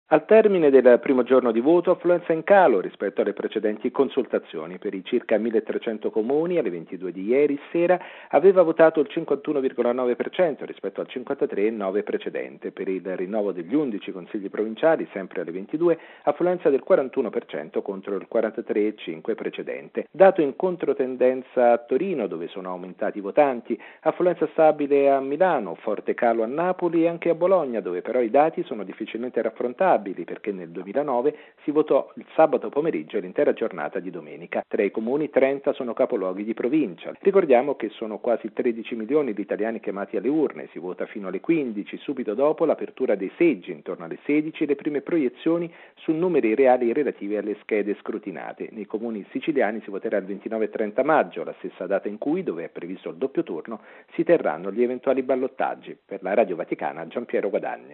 Servizio